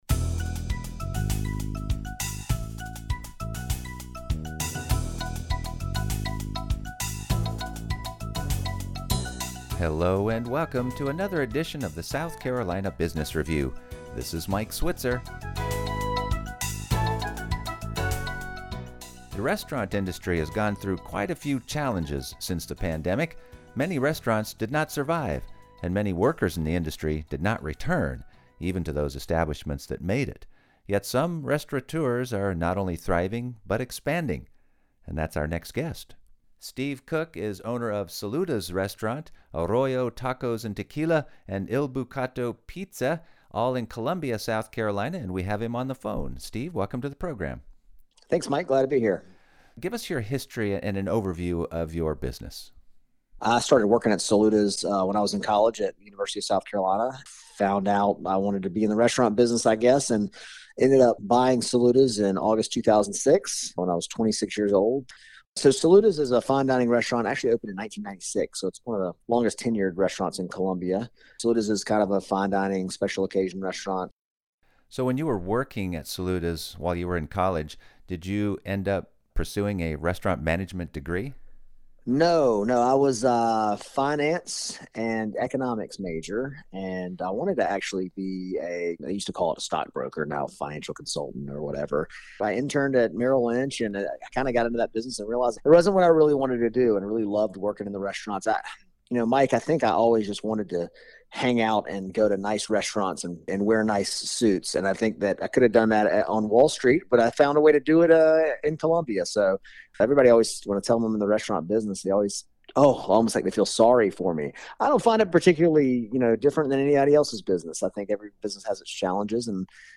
South Carolina's nonprofits, including its colleges and universities are also regularly featured on the program, as well as many of the state's small business support organizations.